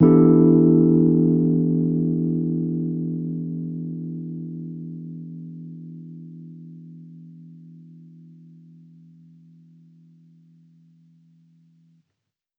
Index of /musicradar/jazz-keys-samples/Chord Hits/Electric Piano 1
JK_ElPiano1_Chord-Em11.wav